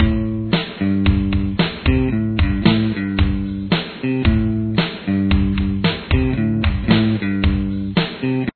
Intro Riff
Guitar 3